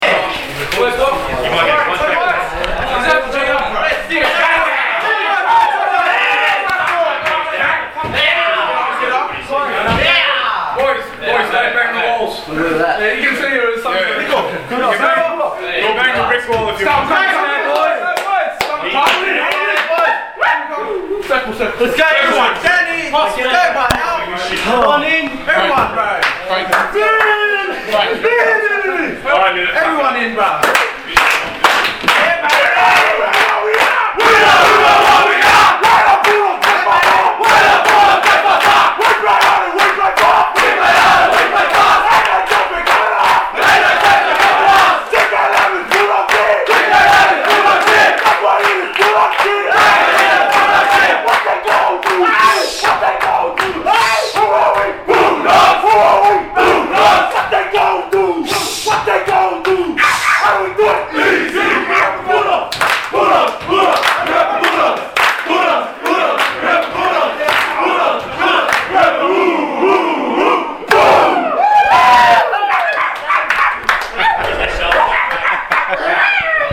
BULLDOGS SING VICTORY SONG !